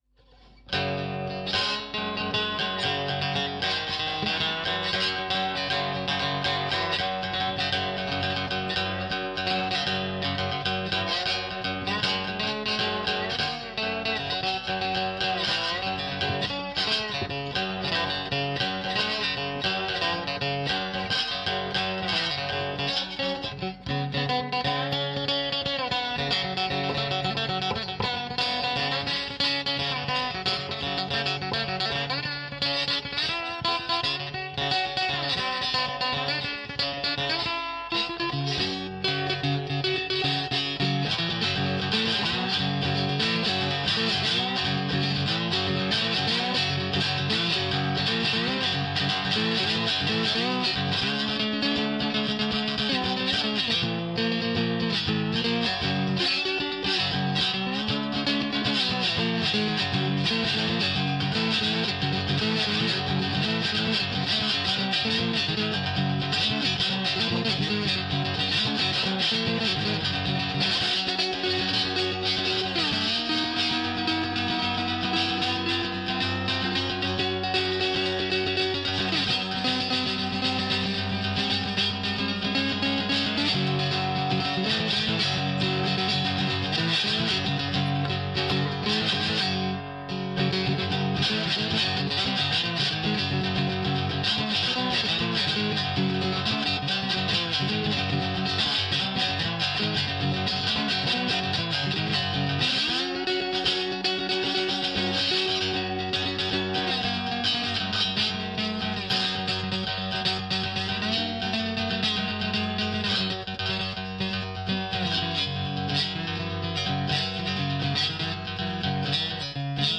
醇厚的吉他2
描述：混合了单音和和弦。真正漂亮的合唱声音。这个是用带效果的声音字体做的。修正了节奏，因为弦乐不可能作为rex文件正确切分。
Tag: 90 bpm Ambient Loops Guitar Electric Loops 3.59 MB wav Key : Unknown